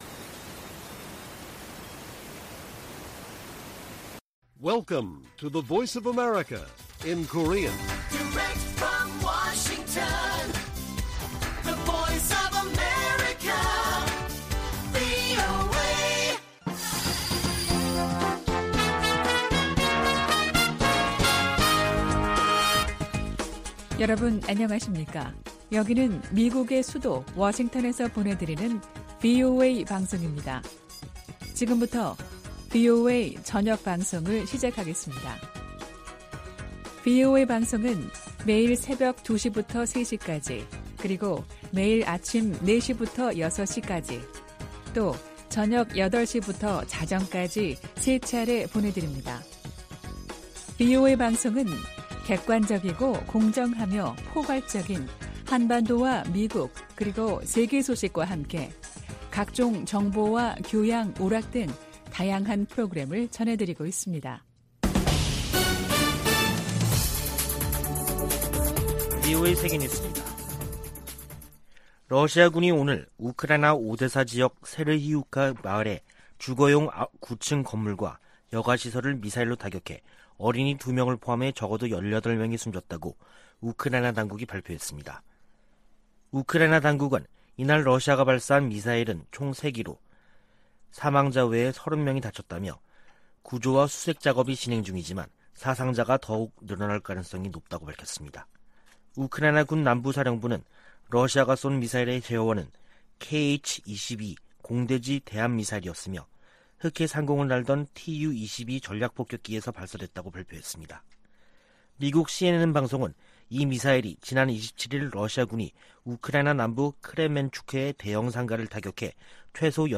VOA 한국어 간판 뉴스 프로그램 '뉴스 투데이', 2022년 7월 1일 1부 방송입니다. 북대서양조약기구(NATO·나토) 정상회의가 막을 내린 가운데 조 바이든 미국 대통령은 ‘역사적’이라고 평가했습니다. 미국의 한반도 전문가들은 윤석열 한국 대통령이 나토 정상회의에서 북핵 문제에 대한 미한일 3각협력 복원 의지를 분명히했다고 평가했습니다. 미 국무부가 미일 동맹 현대화와 미한일 삼각공조 강화 등 일본 전략을 공개했습니다.